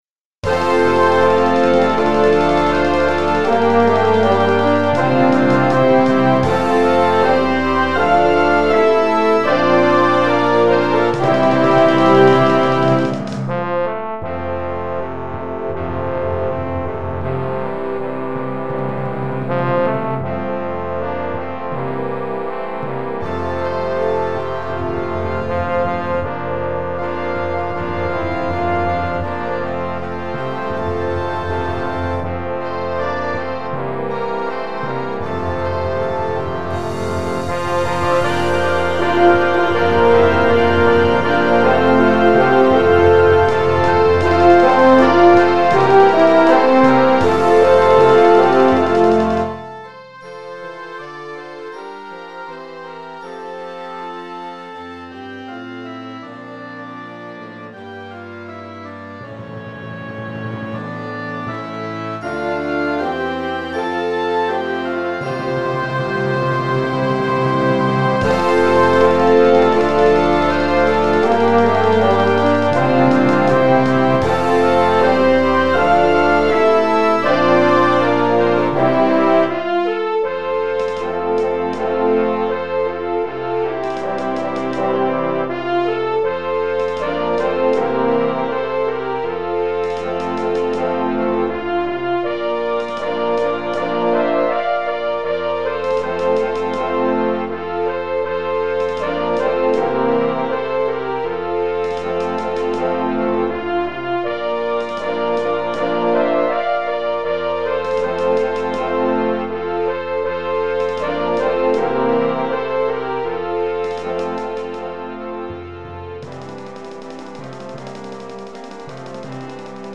Gattung: Moderner Einzeltitel
Besetzung: Blasorchester